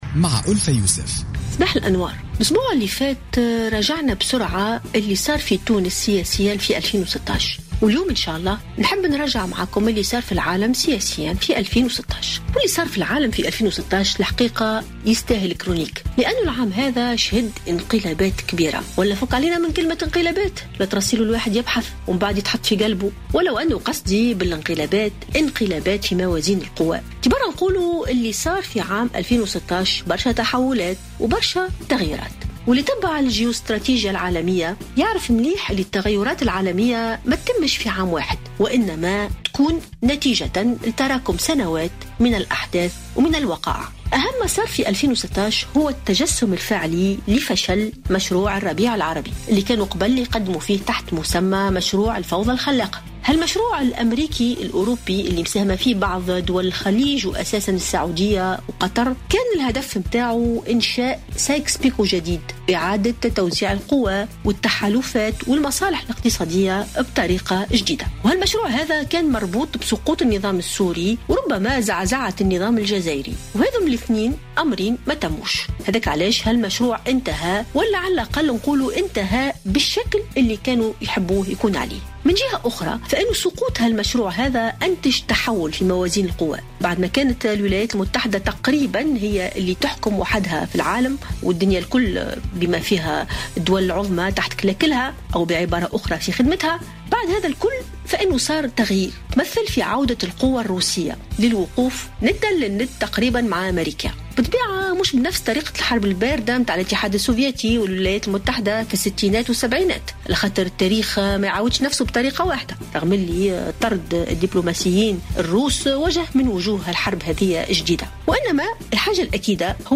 رجّحت الكاتبة ألفة يوسف في افتتاحيتها لـ "الجوهرة أف أم" اليوم الاثنين فشل مشروع الربيع العربي.